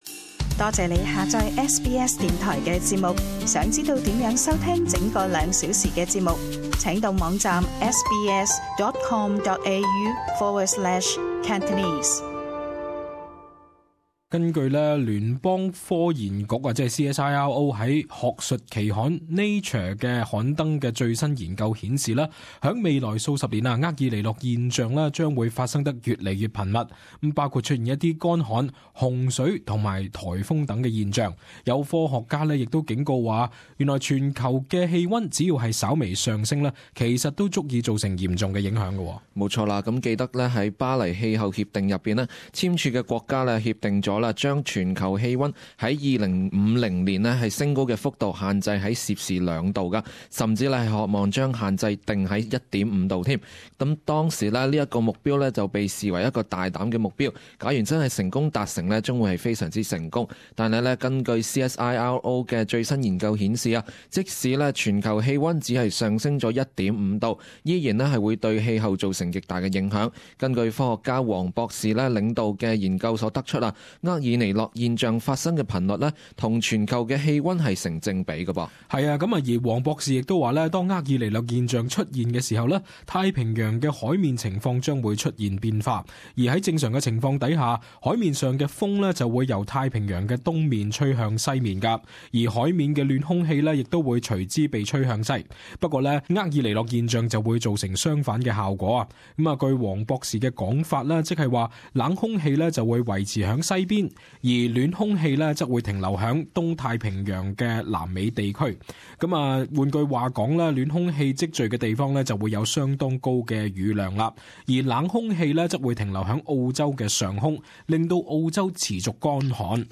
【時事報導】研究：澳洲乾旱將更頻繁